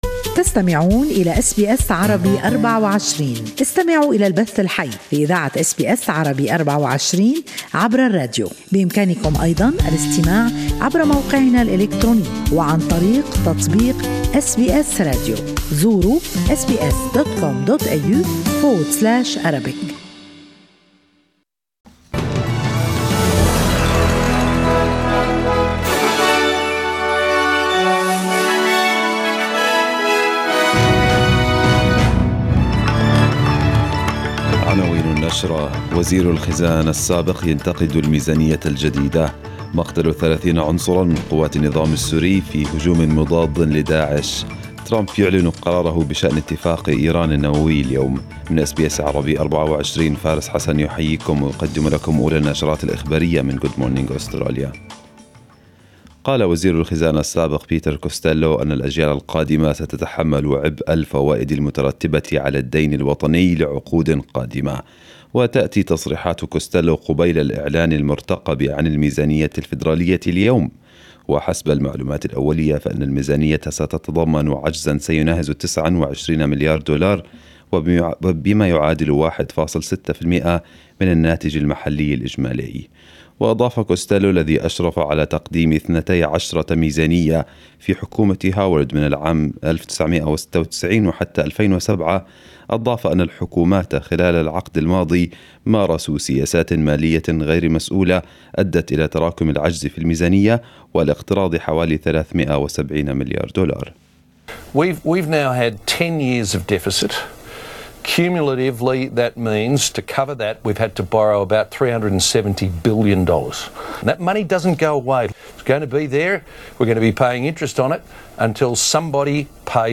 Arabic News Bulletin 08/05/2018